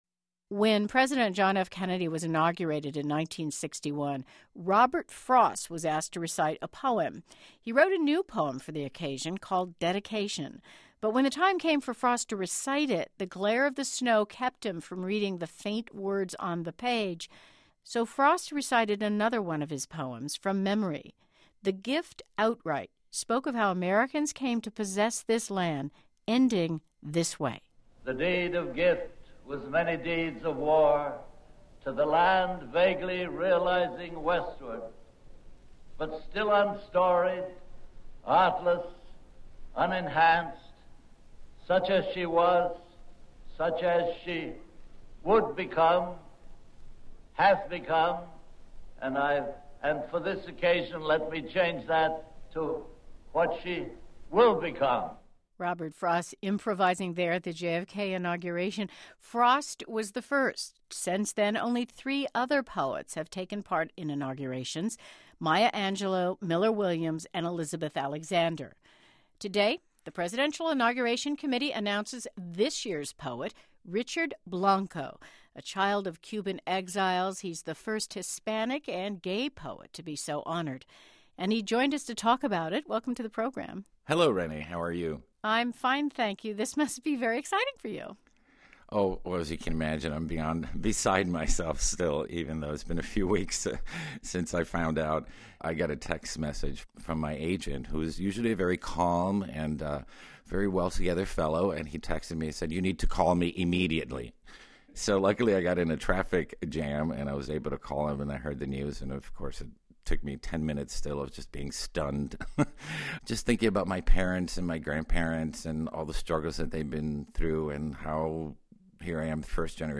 the ghostly and gorgeous voice
the trippy Canadian rock group
Dutch pop singer-songwriter
the infectious, ’60s-inspired psych-pop duo
the electronic dance band